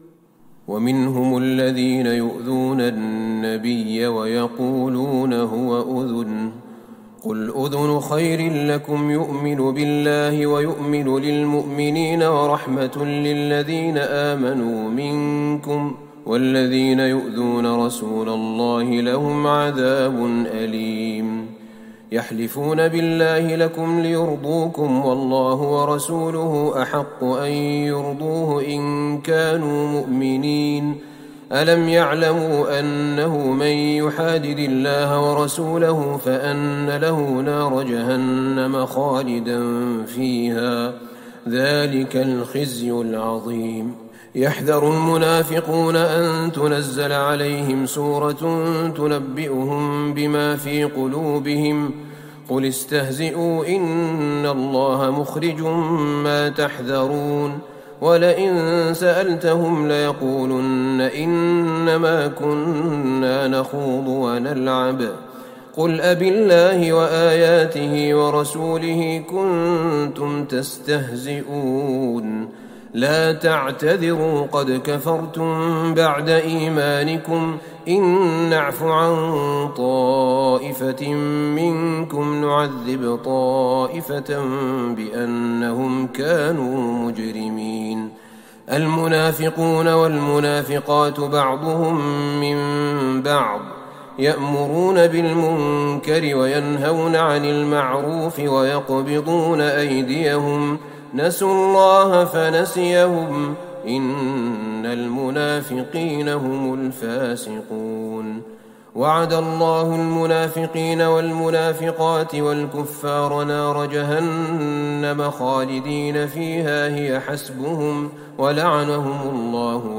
ليلة ١٤ رمضان ١٤٤١هـ من سورة التوبة { ٦١-١١٦ } > تراويح الحرم النبوي عام 1441 🕌 > التراويح - تلاوات الحرمين